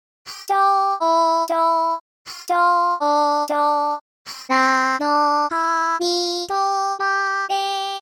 UTAUでのべた打ちファイルを用意します。音程と歌詞以外の一切の設定をしていません。